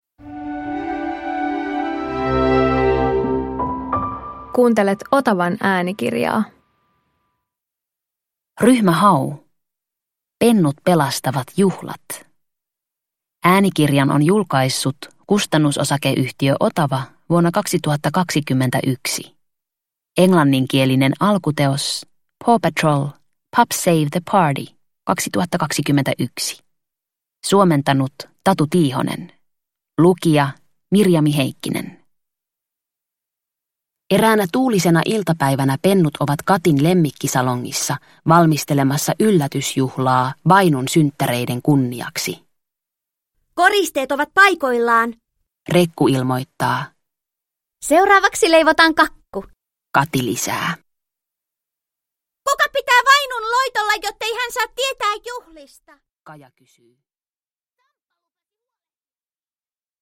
Ryhmä Hau - Pennut pelastavat juhlat – Ljudbok – Laddas ner
Vauhdikas äänikirja Ryhmä Haun seikkailuista. Ryhmä Hau päättää yllättää Vainun syntymäpäiväjuhlilla. Mutta kun tuulivoimalan turbiini hajoaa, koko Jännälahdesta katkeavat sähköt.